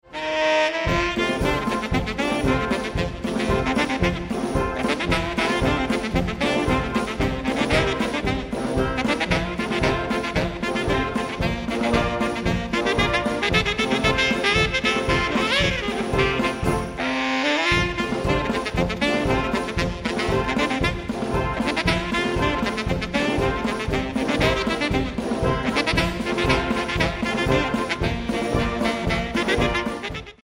Solo für Tenorsaxophon und Blasorchester Schwierigkeit
Besetzung: Blasorchester